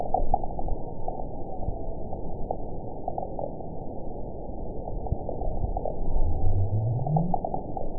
event 922347 date 12/30/24 time 01:58:07 GMT (11 months ago) score 9.49 location TSS-AB03 detected by nrw target species NRW annotations +NRW Spectrogram: Frequency (kHz) vs. Time (s) audio not available .wav